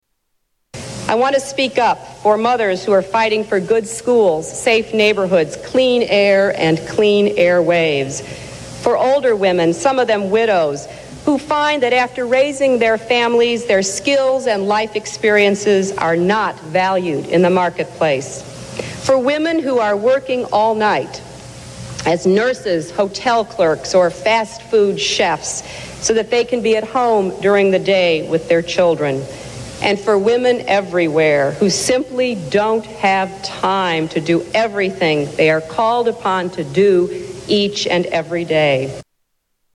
Hillary Clinton speaks for women everywhere